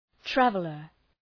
{‘trævələr}